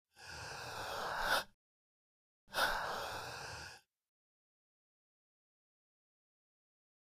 Wheezing
Deep Airy Breathing Through Mouth